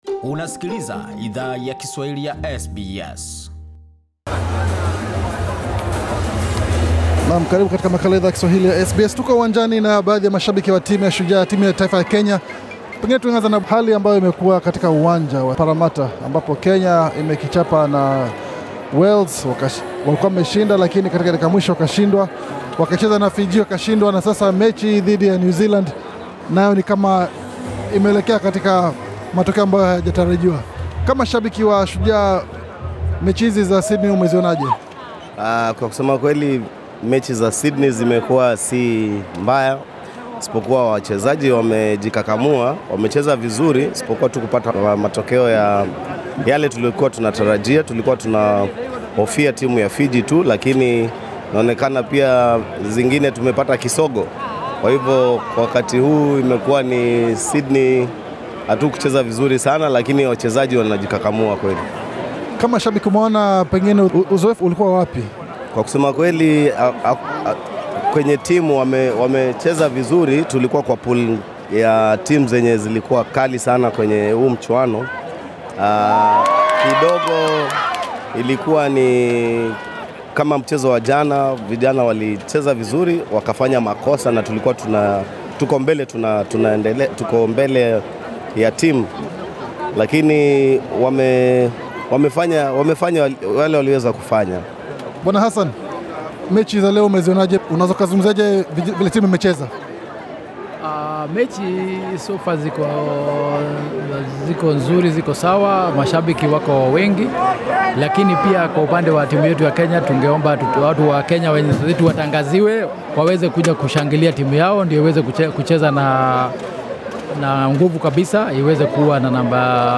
SBS Swahili ilihudhuria michuano hiyo nakuzungumza na baadhi ya mashabiki hao, katika uwanja wa Bankwest mjini Parramatta, New South Wales.